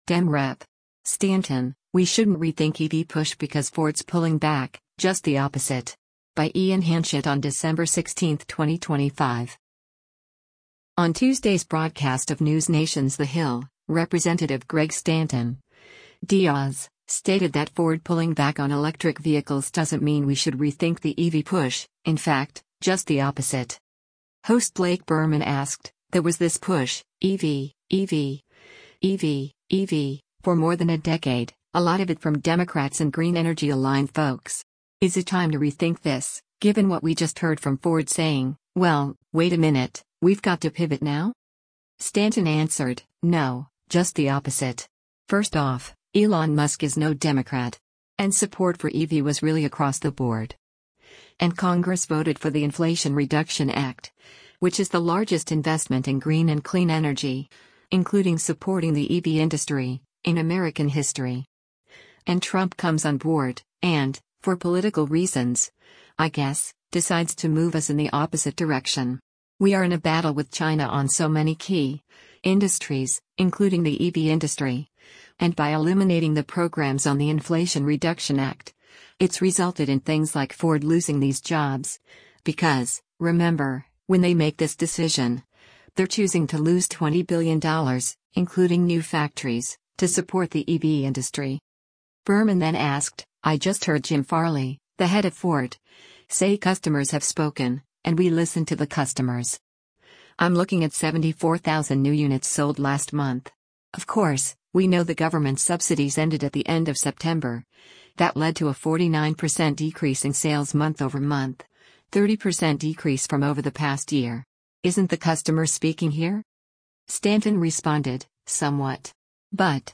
On Tuesday’s broadcast of NewsNation’s “The Hill,” Rep. Greg Stanton (D-AZ) stated that Ford pulling back on electric vehicles doesn’t mean we should rethink the EV push, in fact, “just the opposite.”